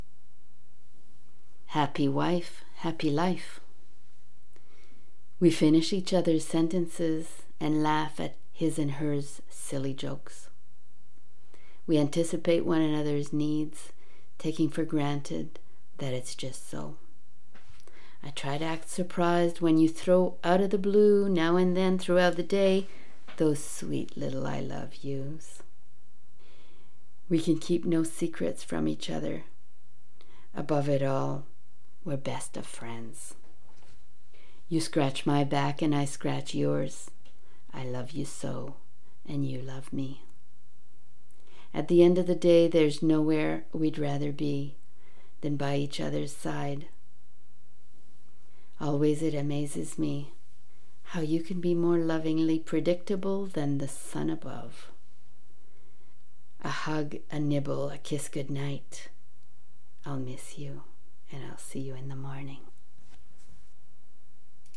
Read on air by invitation  ~  May 5, 2021  'WORDS & MUSIC'